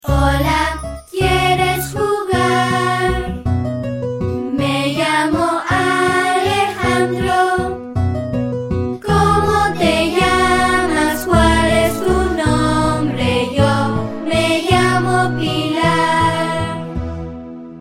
Spanish Song for Teaching About Colors